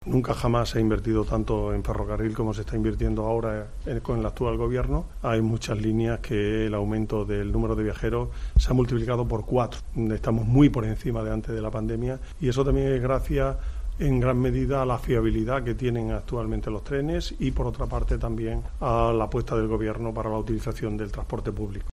Son declaraciones del delegado del Gobierno, José Luis Quintana.